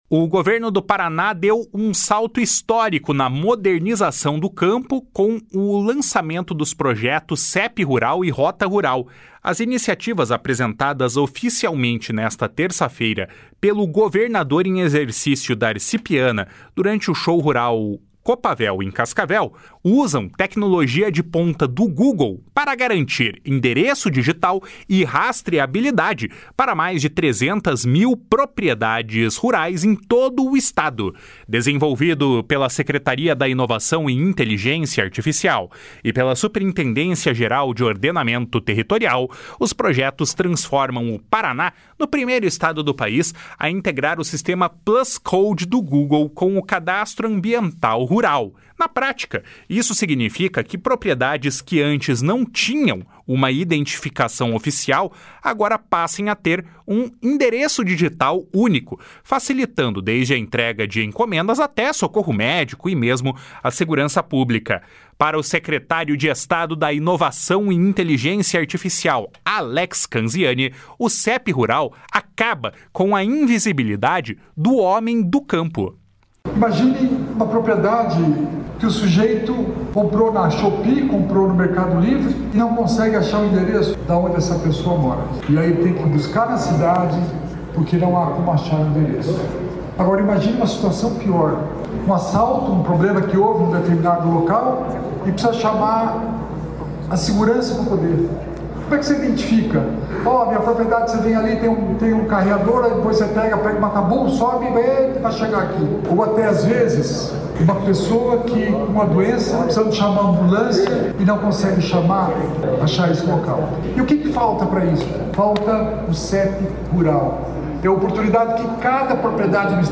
Para o secretário de Estado da Inovação e Inteligência Artificial, Alex Canziani, o CEP Rural vai acabar com a “invisibilidade” do homem do campo. // SONORA ALEX CANZIANI //